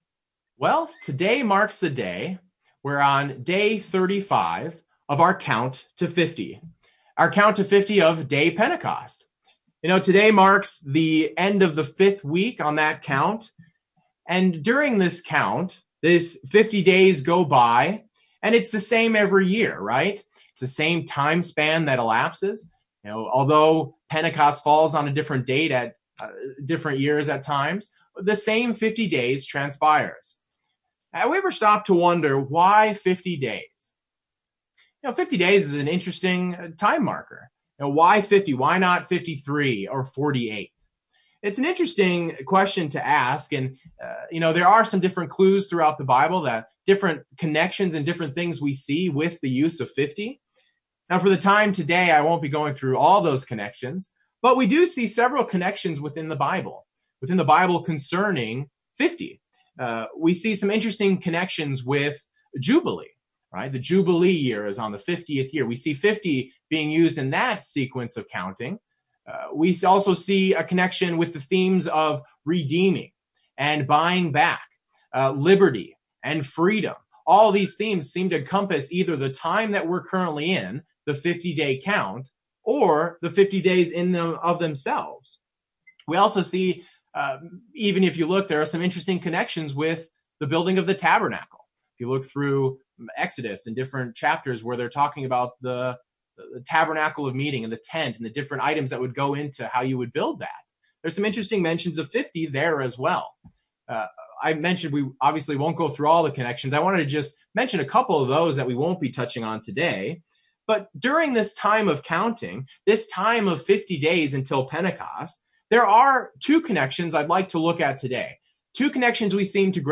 During our count to Pentecost, many connections and considerations can come to mind. In this sermon, we review two considerations of the time we are in -- we are in a time of harvest, and a time of labor.